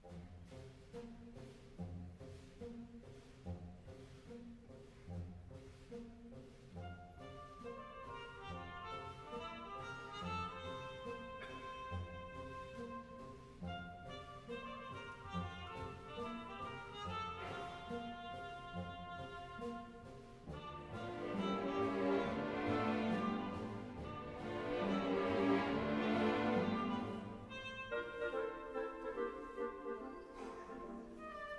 It incorporates jazz and swing elements into an orchestral piece.